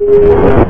portal02.ogg